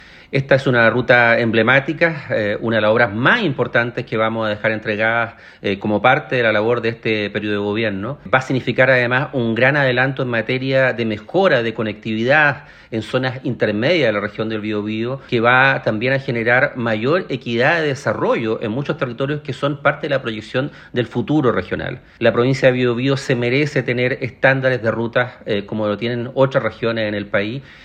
Consultado sobre esta situación, Hugo Cautivo, seremi de Obras Públicas del Bío Bío, indicó que esta ruta entregará un alto estándar y dará mucha más seguridad a quienes transitan por ella.